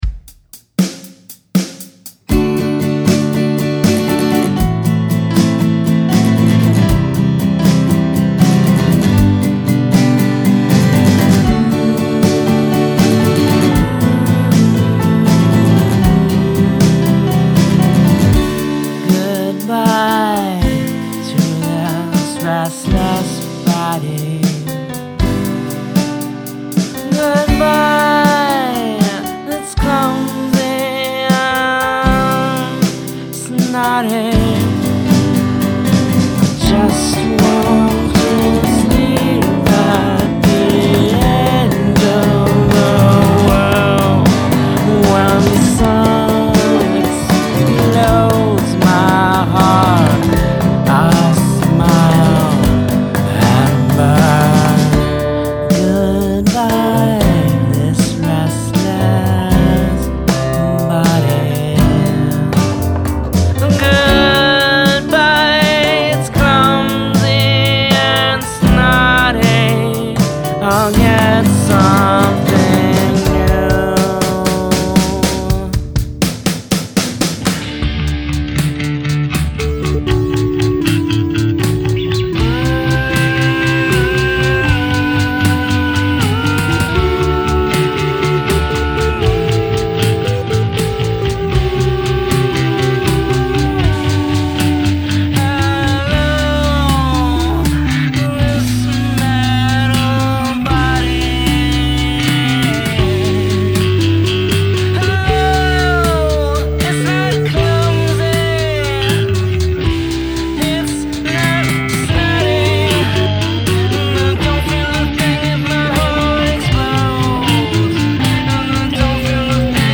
Acoustic guitar/Electric Guitar
Piano/Synth(s)
Normal voice/robo voice